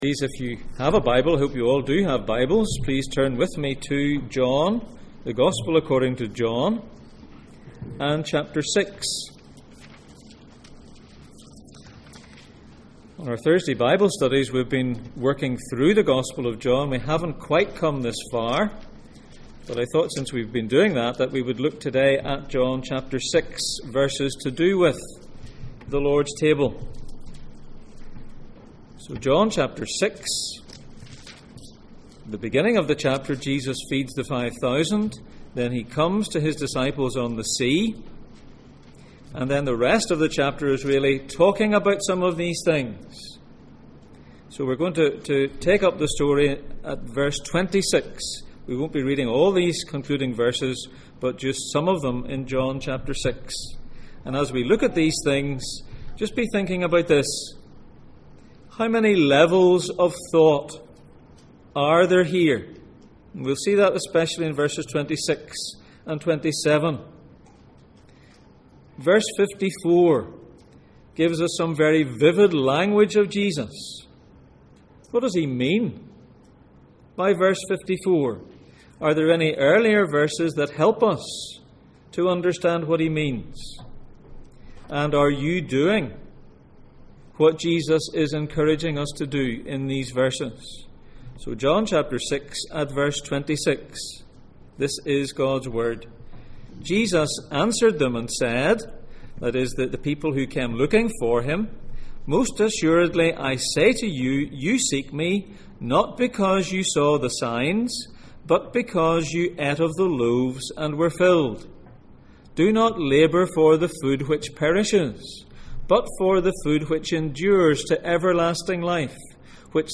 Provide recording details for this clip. The Lord's Table Passage: John 6:26-59, John 6:60-63 Service Type: Sunday Morning %todo_render% « Baptising babies